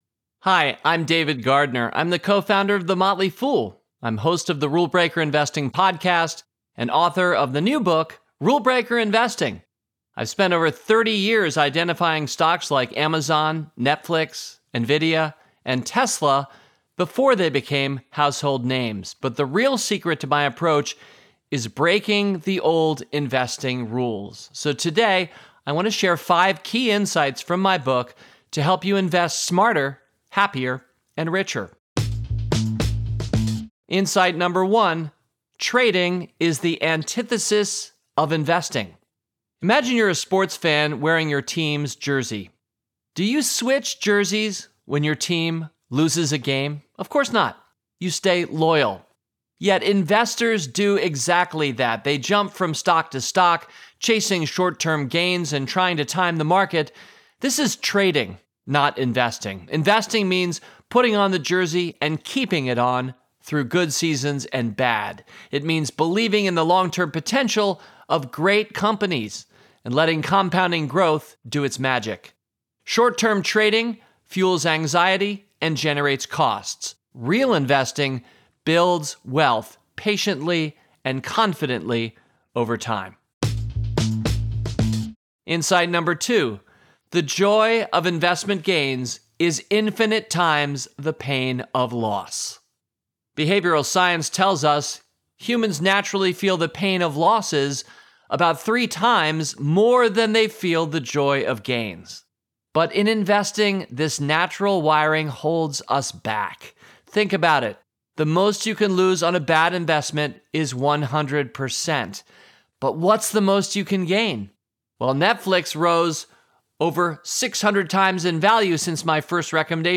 Listen to the audio version of this Book Bite—read by David himself—below, or in the Next Big Idea App.